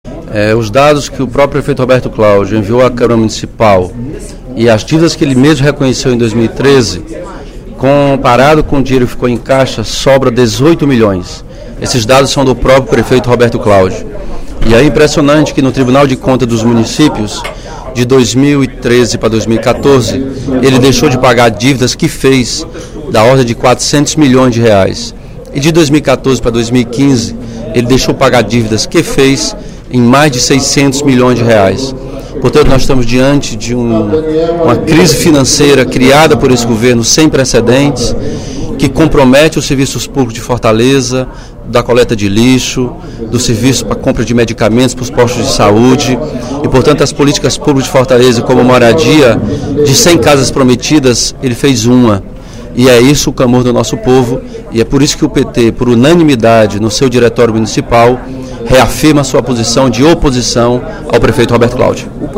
O deputado Elmano Freitas (PT) ressaltou, no primeiro expediente da sessão plenária da Assembleia Legislativa desta quinta-feira (25/06), a oposição do Diretório Municipal do Partido dos Trabalhadores de Fortaleza à gestão do prefeito Roberto Cláudio.